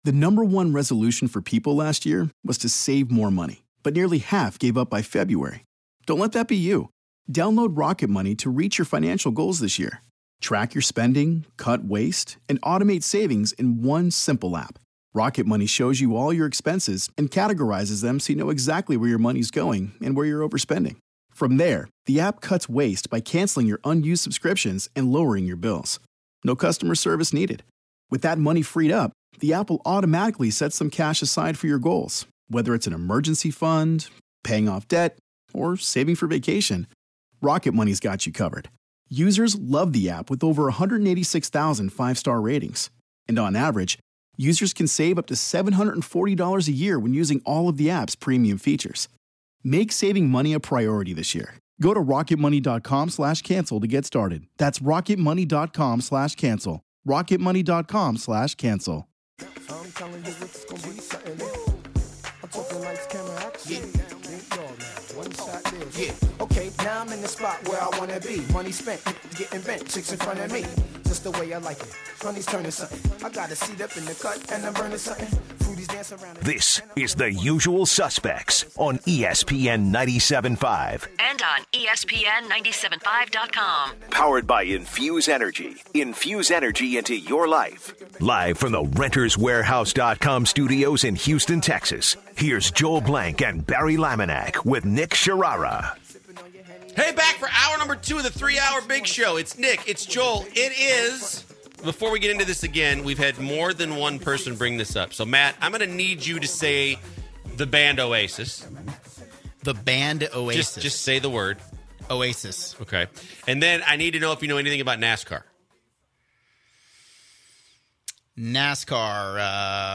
They take in calls about Jimmy Garoppolo and Todd Gurley. They talk about why the Philadelphia Eagles are not favored to win the Super Bowl during the middle of the hour and compare them to the Cowboys. The hour wraps up calling out Jeff Luhnow to make more offers for the Astros before all the good players are off the market.